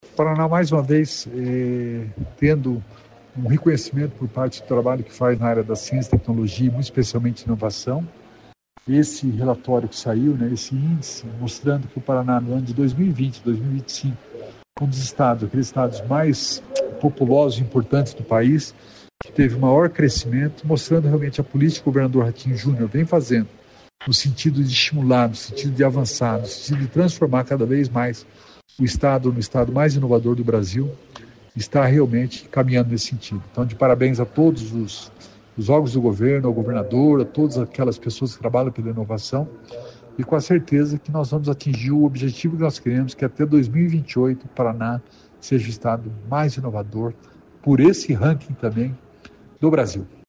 Sonora do secretário Estadual da Inovação e Inteligência Artificial, Alex Canziani, sobre o 3º lugar no Índice Brasil de Inovação e Desenvolvimento